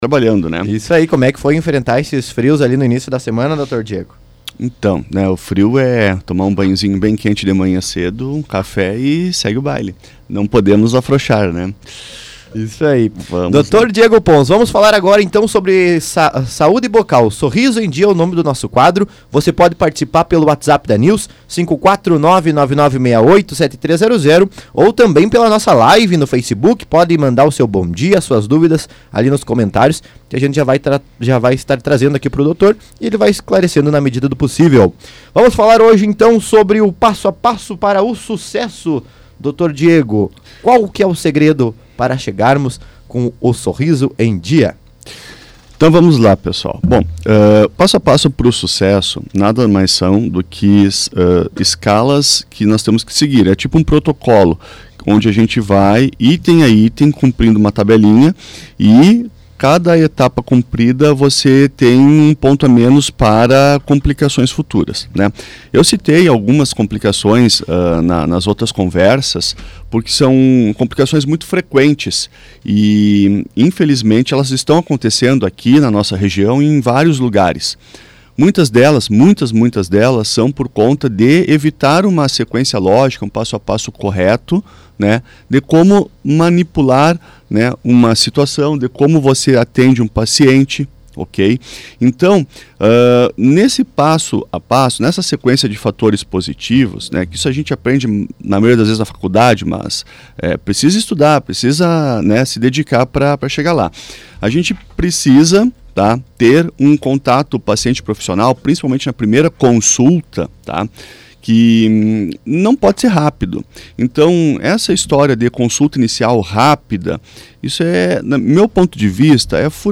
Na edição desta quinta-feira (03) do quadro “Sorriso em Dia”, transmitido pela Planalto News FM 92.1, o tema central foi o segredo para o sucesso nos tratamentos odontológicos, com foco na fase de planejamento e construção do sorriso. O conteúdo destacou a importância da relação entre paciente e profissional, que deve ser baseada em escuta atenta, análise criteriosa e decisões compartilhadas.